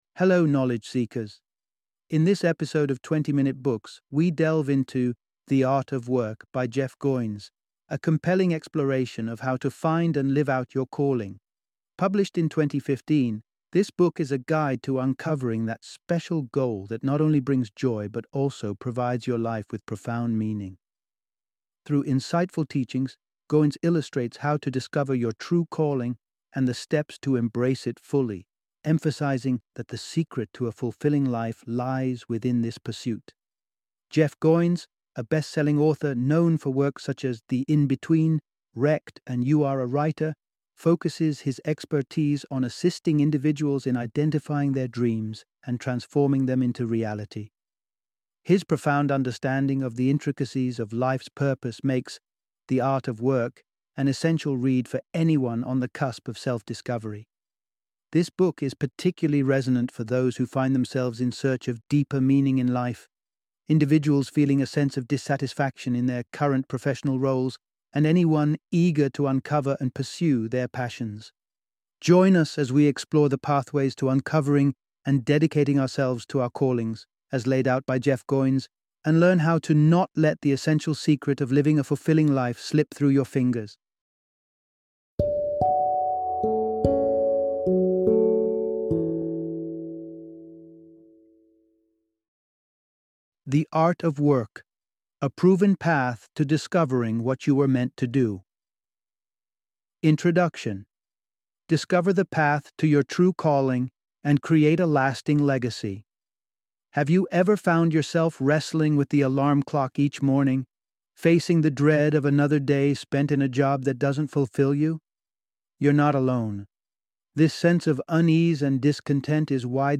The Art of Work - Audiobook Summary